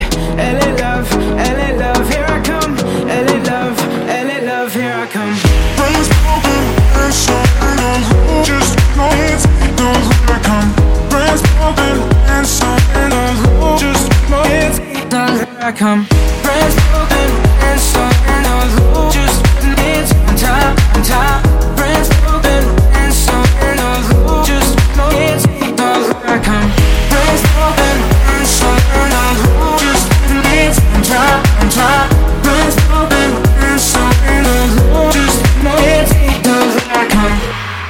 • Качество: 320, Stereo
deep house
спокойные
tropical house
Стиль: chillout, deep, tropical